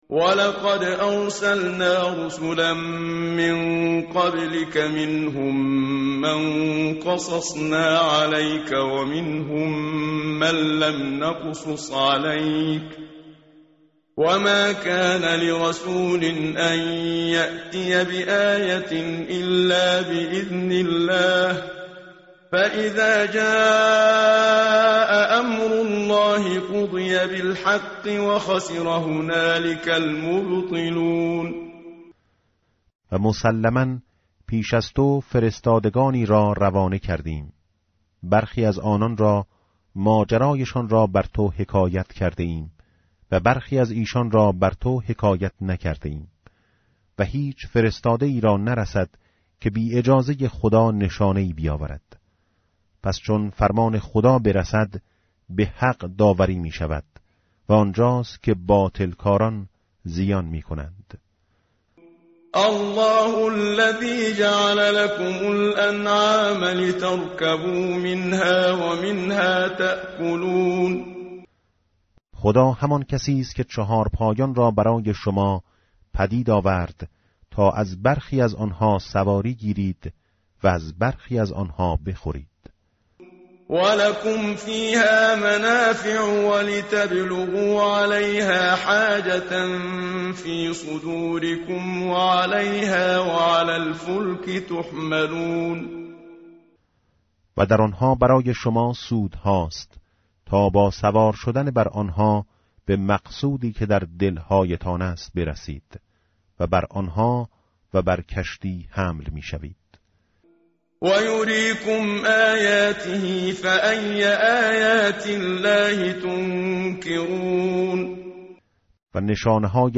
tartil_menshavi va tarjome_Page_476.mp3